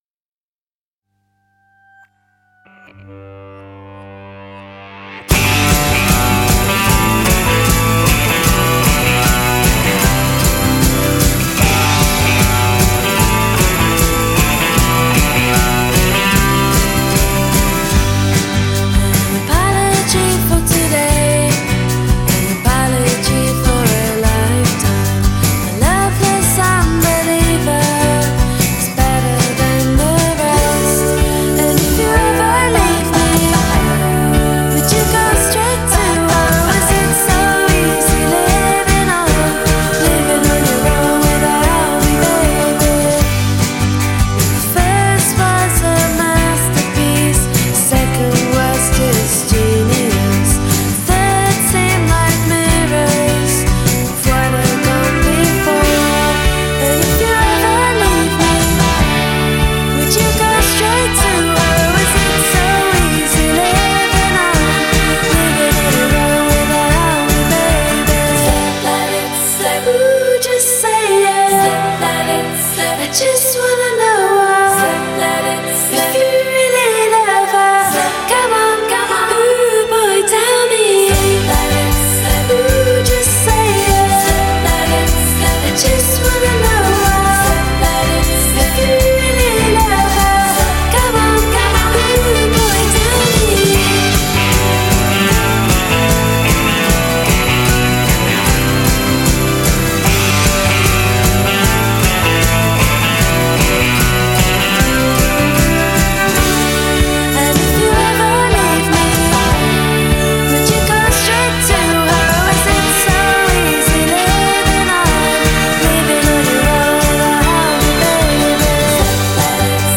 British pop band
60’s girl group sound
This stuff really does feel 1963.